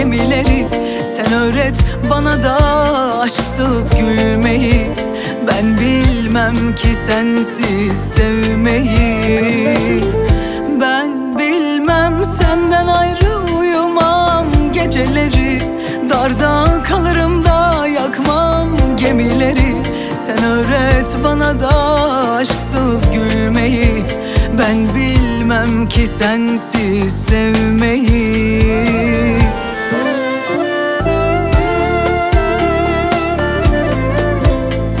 Жанр: Турецкая поп-музыка / Поп / Русские
# Turkish Pop